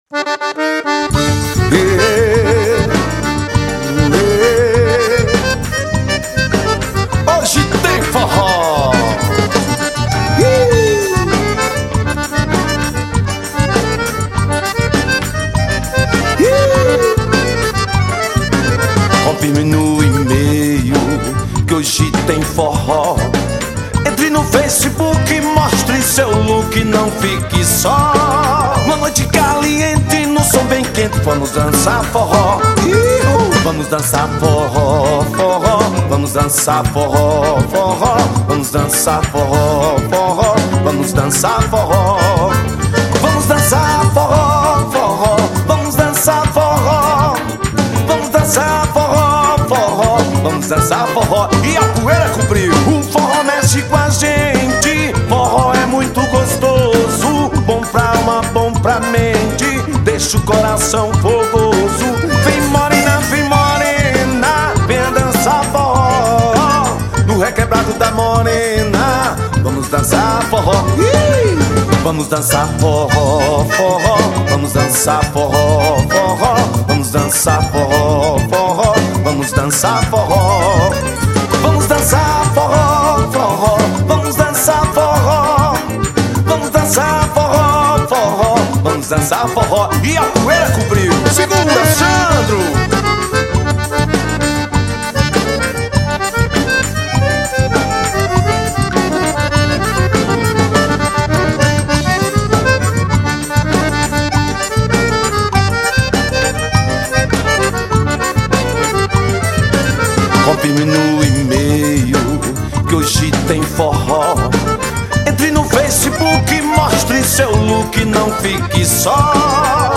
CD ao vivo